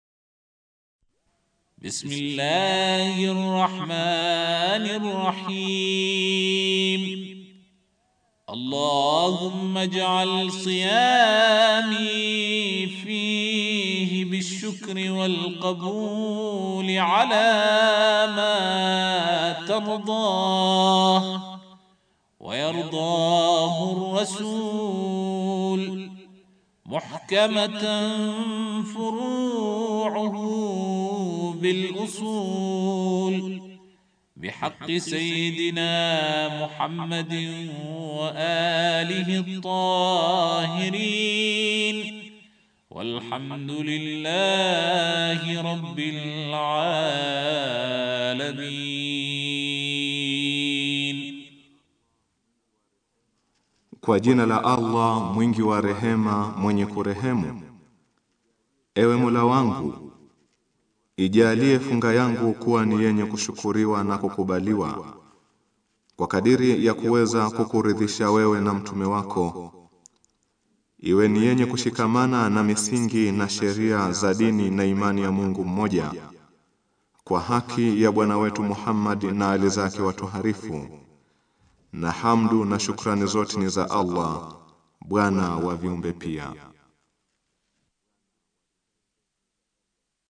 Dua ya siku ya thelathini ya Ramadhani